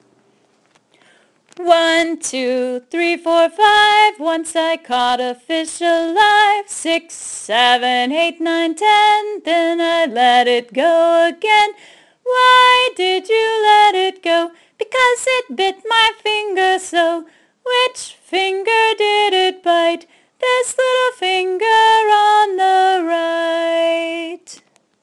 An easy counting fingerplay.  Click on the triangle for the tune: